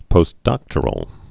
(pōst-dŏktər-əl) also post·doc·tor·ate (-ĭt)